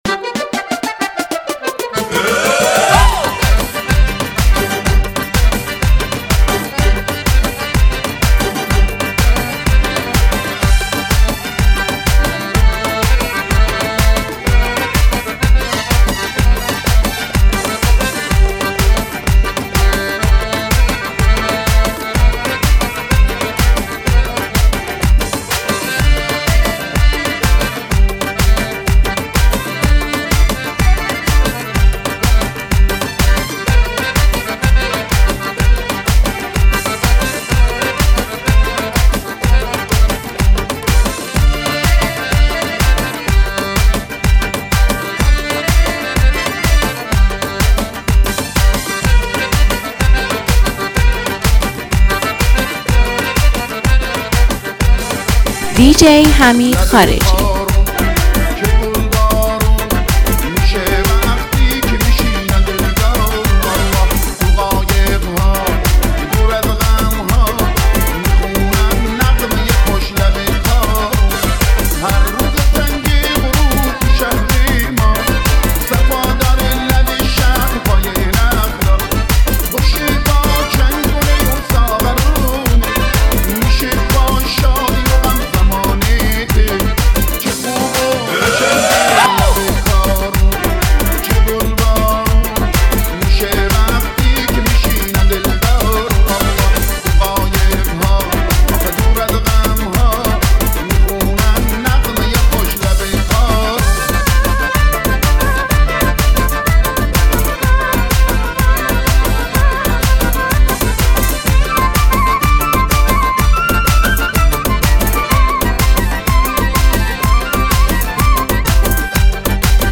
نوستالژی کوچه بازاری با ریتم مدرن
ترکیبی از حس نوستالژی و ریتم‌های شنیدنی است